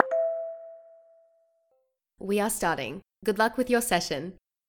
focus_alert_1.wav